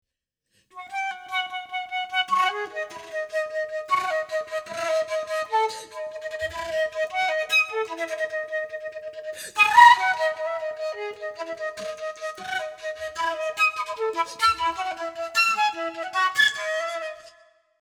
This flute solo offers everything the advanced flutist could want: aggressive passages, sweeping gestures, expressive grace notes, and flutter tonguing.
It uses the full range of the flute in dynamics and pitch as well as several extended techniques.
unaccompanied solo